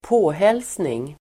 påhälsning substantiv, (unwelcome) visit Uttal: [²p'å:hel:sning] Böjningar: påhälsningen, påhälsningar Synonymer: besök Definition: (ovälkommet) besök Exempel: få påhälsning av tjuvar (be paid a visit by thieves)